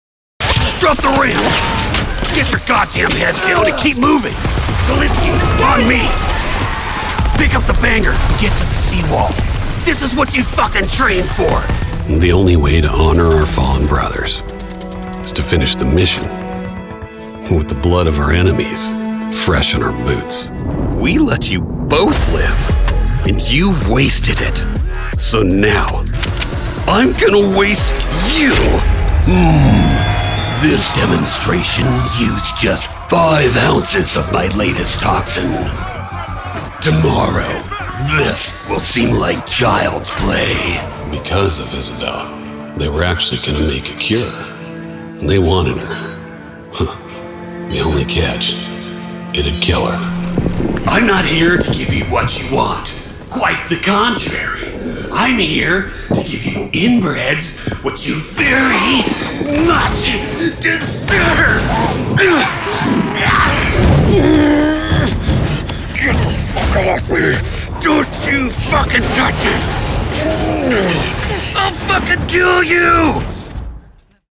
Video Game Demo Reel.mp3